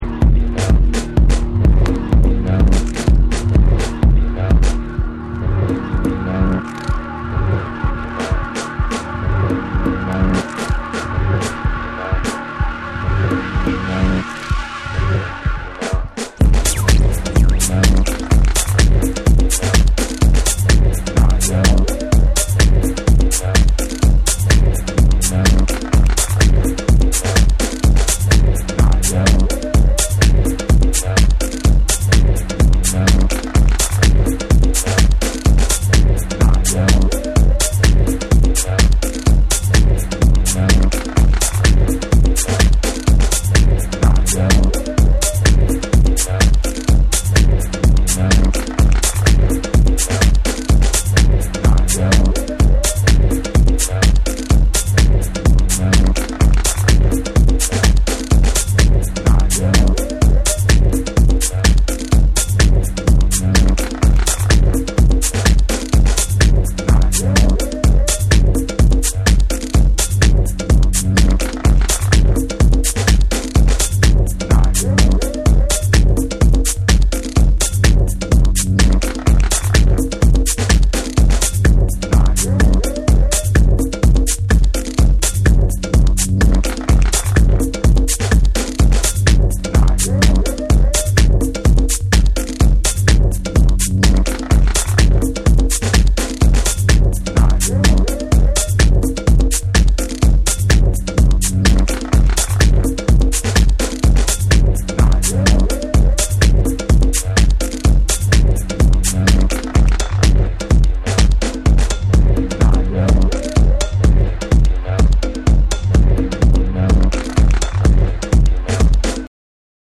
ダビーな空気感とパーカッシヴなリズムが心地よく絡むテック・ハウス
TECHNO & HOUSE / ORGANIC GROOVE